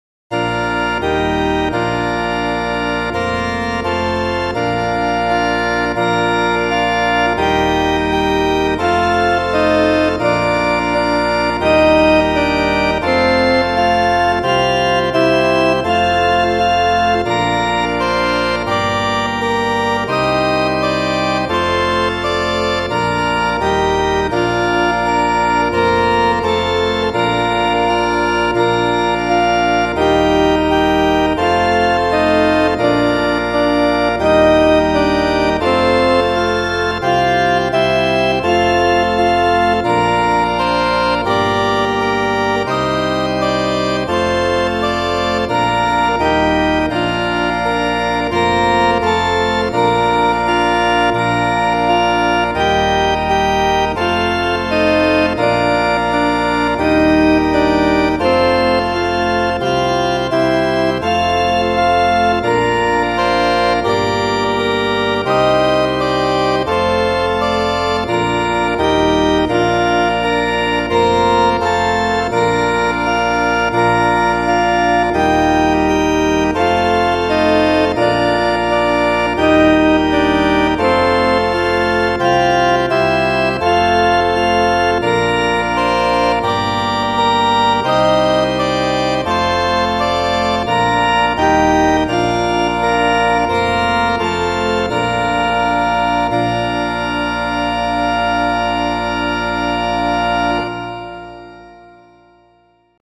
Some BIAB fake organ: